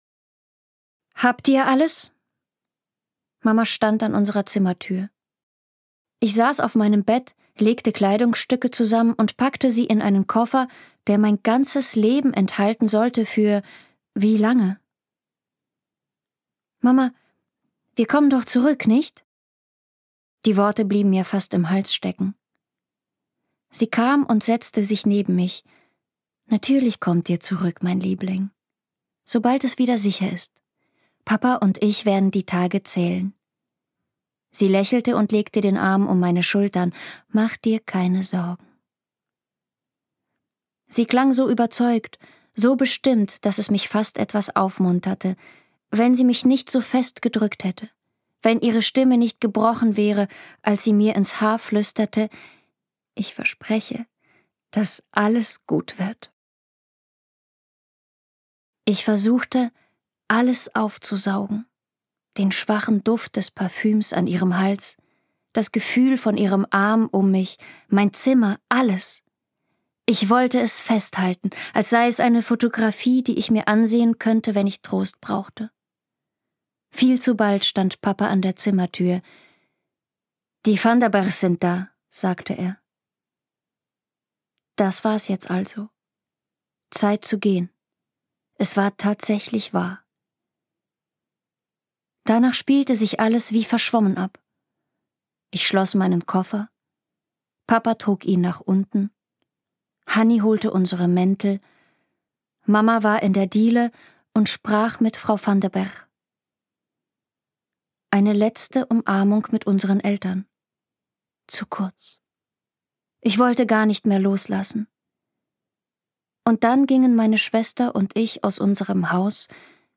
Berührendes Jugendhörbuch über Mut, Hoffnung und Tapferkeit in Kriegszeiten
Rau und ein wenig verletzlich, unverkennbar und sehr präsent – mit ihrer jungen Stimme lässt sie Hörbücher lebendig werden.
Benito Bause, bekannt aus Doppelhaushälfte und All you need, ist zudem ein versierter Theaterschauspieler und gibt mit seiner jugendlich weichen Stimme seinen Hörbuchinterpretationen einen intensiven, nachhallenden Klang.